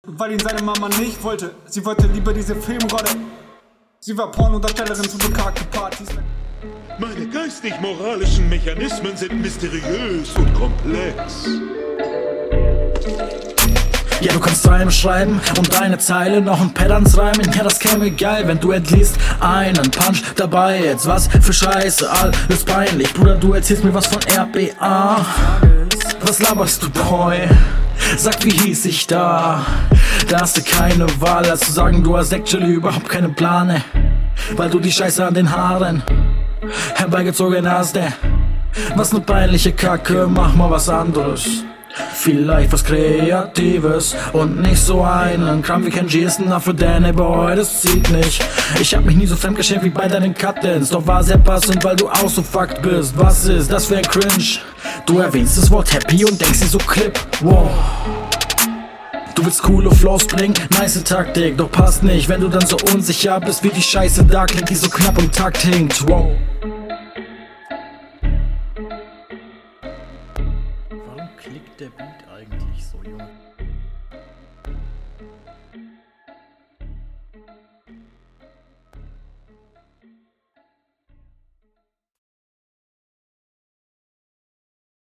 ok ist der beat der knackt. hab mich schon gewundert hahaha. flowst an sich cooler …